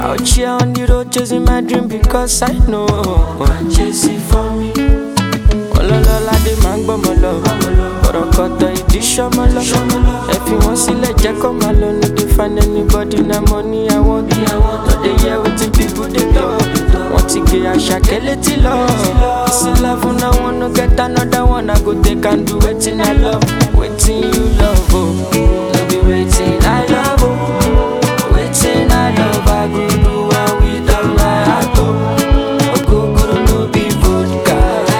Жанр: Африканская музыка / Русские